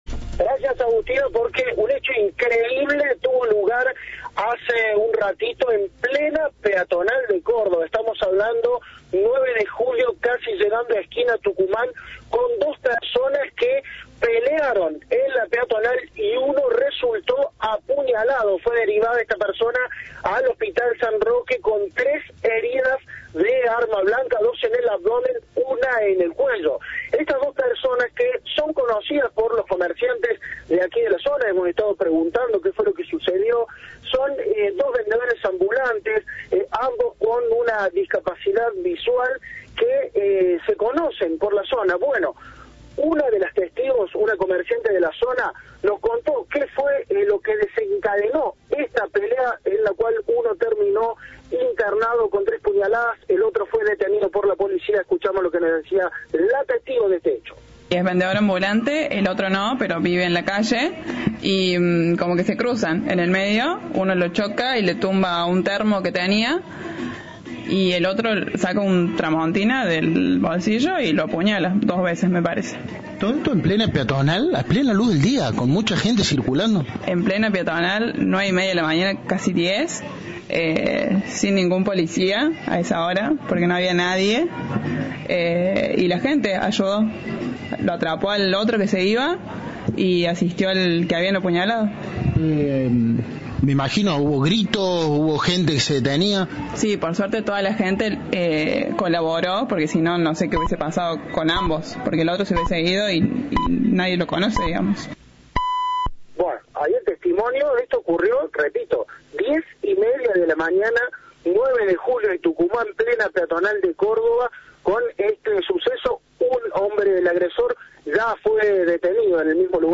Una testigo relató el hecho en Cadena 3
Una comerciante de la zona, testigo del hecho, contó a Cadena 3 cómo sucedió la pelea.